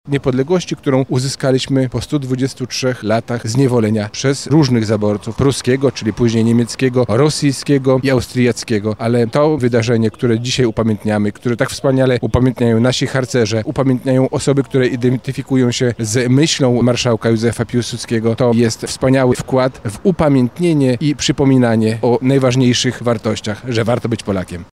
Ta wyprawa Legionistów przyczyniła się do tego, że trzy lata później, w 1918 roku, odzyskaliśmy niepodległość – mówi Robert Gmitruczuk, wicewojewoda lubelski: